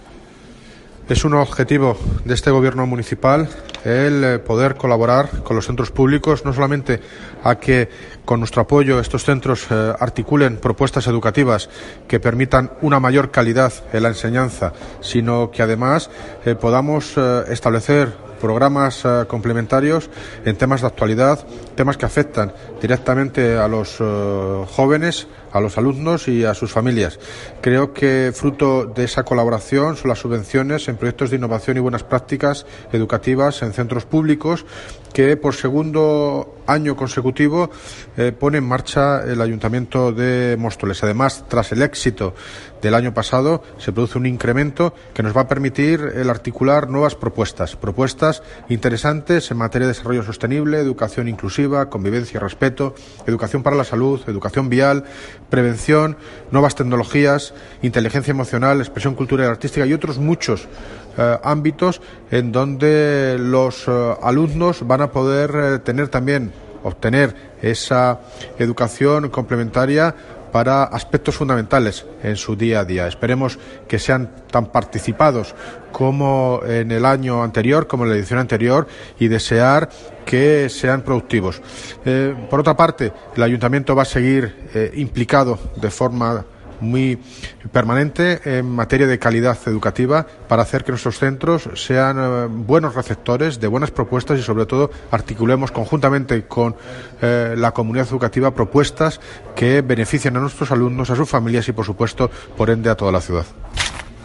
Audio - David Lucas (Alcalde de Móstoles) Sobre subvenciones a proyectos educativos